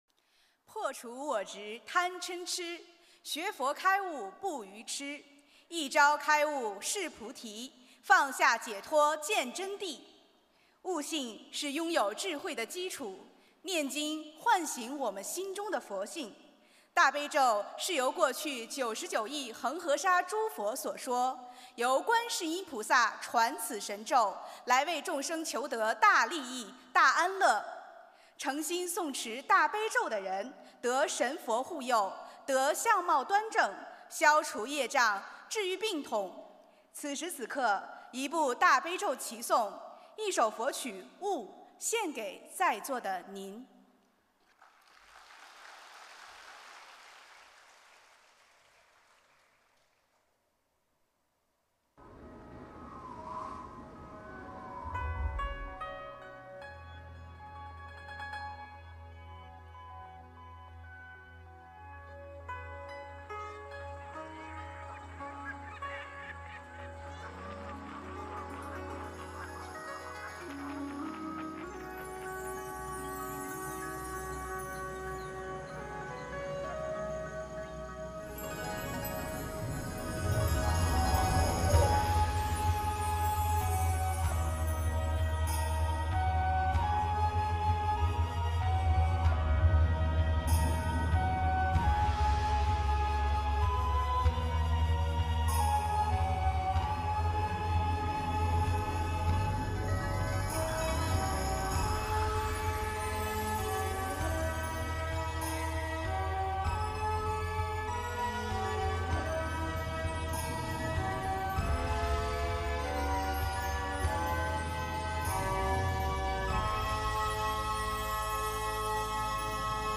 音频：《大悲咒齐诵》=新加坡一叶一菩提千人素食分享会！